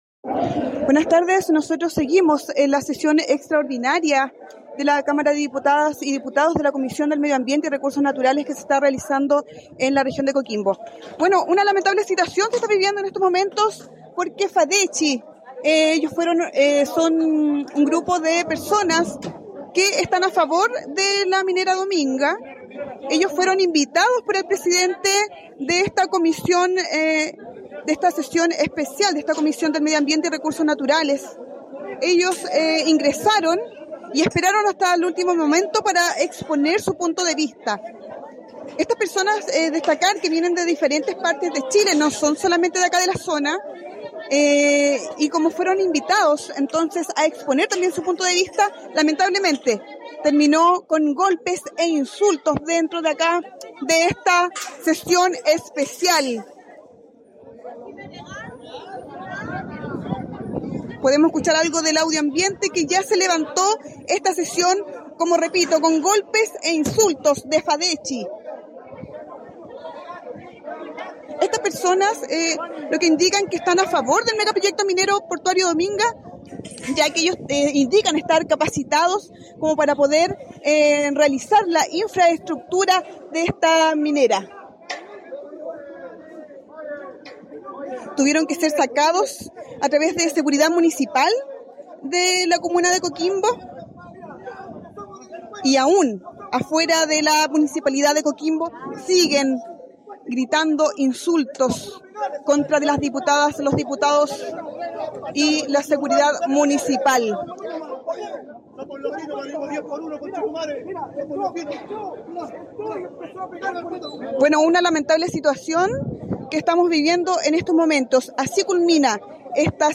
Sesión desde Coquimbo